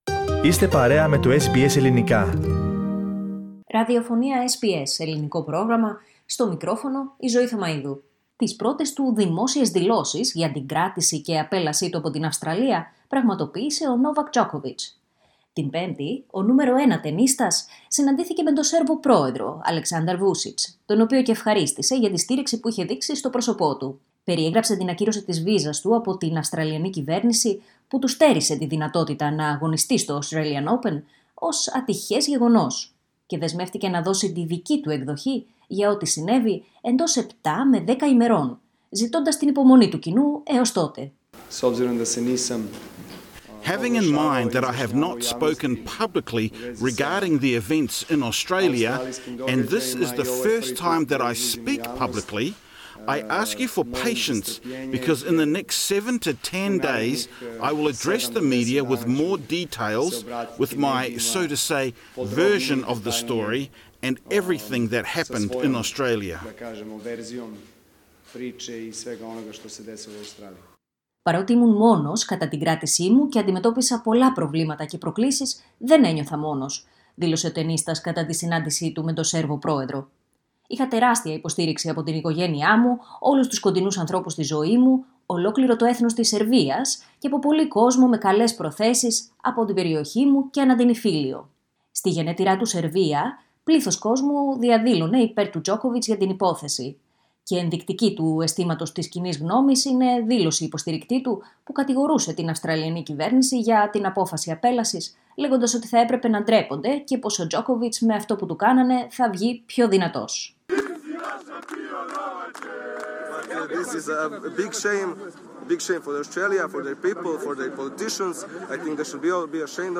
Άκου τις δηλώσεις Djokovic και τις τελευταίες εξελίξεις στο podcast